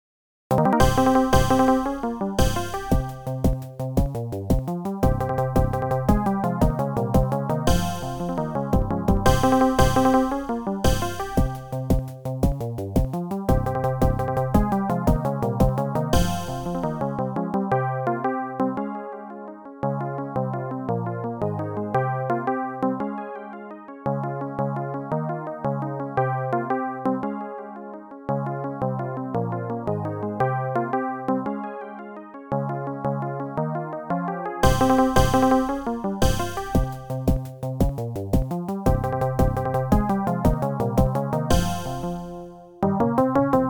AdLib MUS